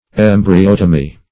Meaning of embryotomy. embryotomy synonyms, pronunciation, spelling and more from Free Dictionary.
Search Result for " embryotomy" : The Collaborative International Dictionary of English v.0.48: Embryotomy \Em`bry*ot"o*my\, n. [Gr.